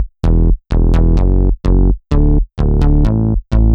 Index of /musicradar/french-house-chillout-samples/128bpm/Instruments
FHC_MunchBass_128-E.wav